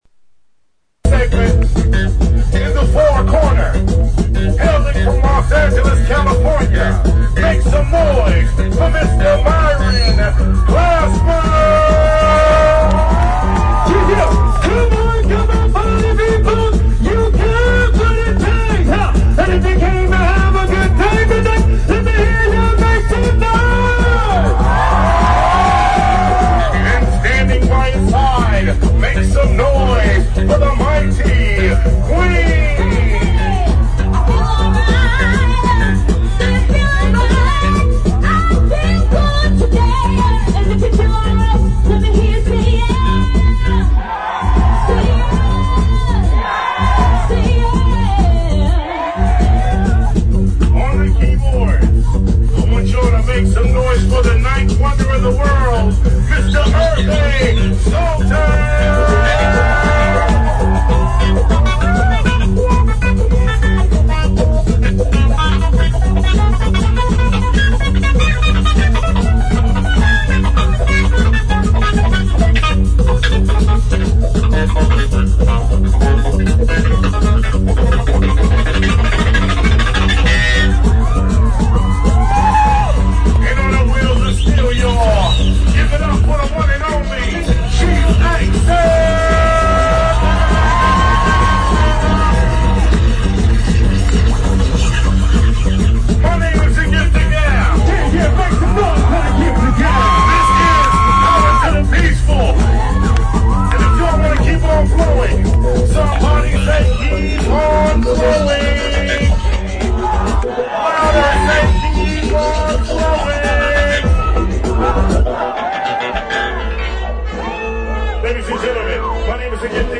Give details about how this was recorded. whipped the Speedway Meadows crowd into a frenzy